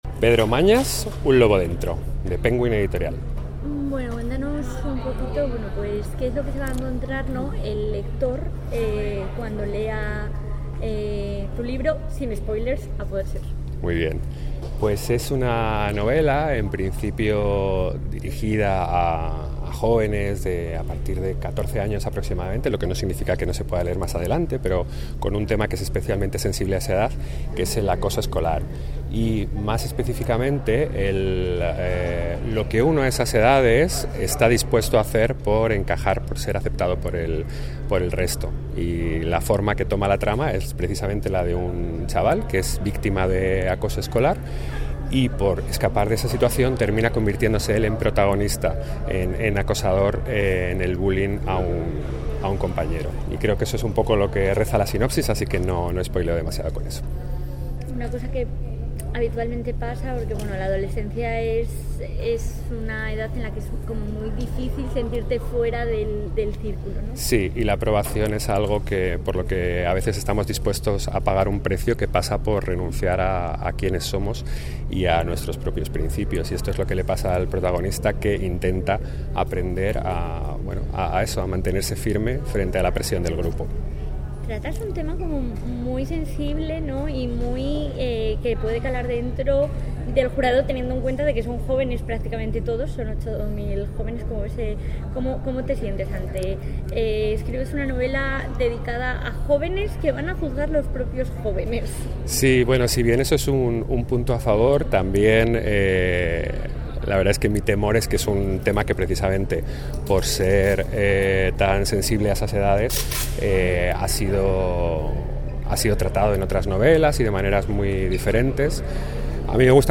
Audio: Declaraciones de los finalistas.